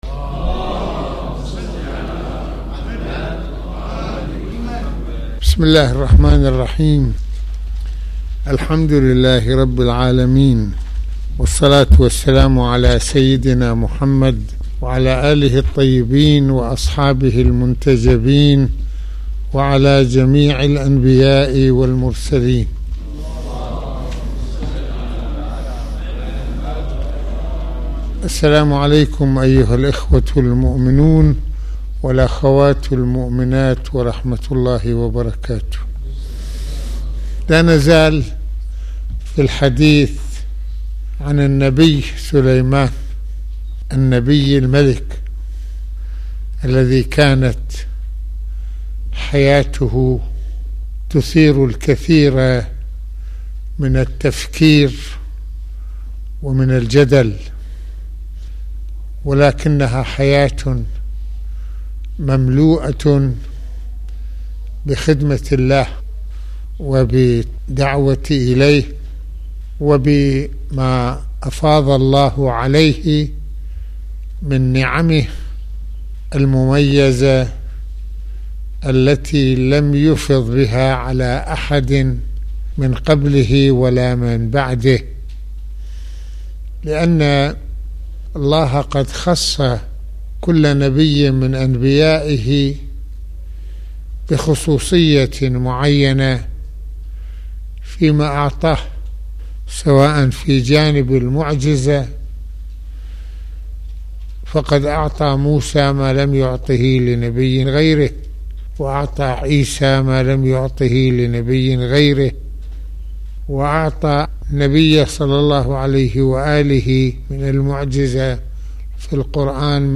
- المناسبة : ندوة السبت المكان : الشام - السيدة زينب(ع) المدة : 36د | 22ث المواضيع : الخصوصيات التي اختص الله تعالى بها كل نبي من الانبياء؟